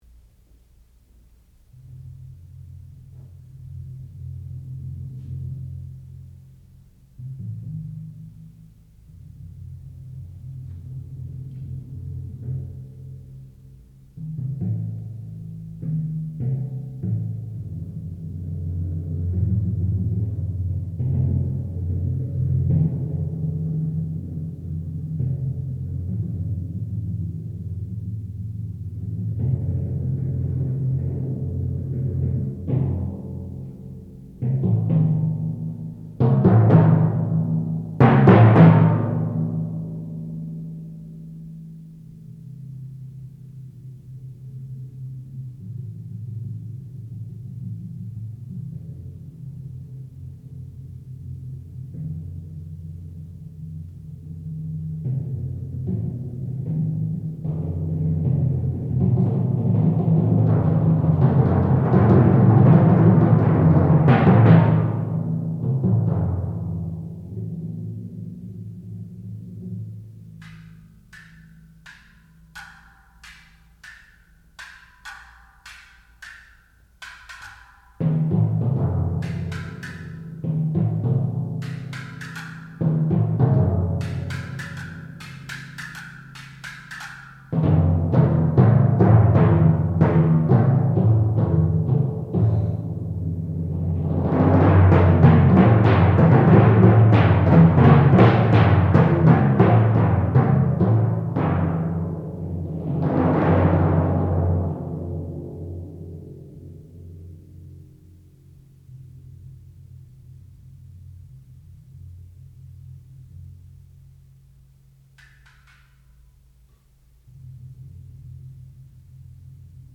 classical music
timpani
Master's Recital